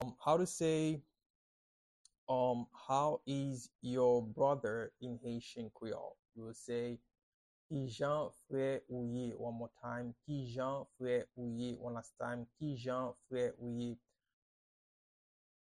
Pronunciation and Transcript:
How-is-your-brother-in-Haitian-Creole-–-Kijan-fre-ou-ye-pronunciation-by-a-Haitian-teacher.mp3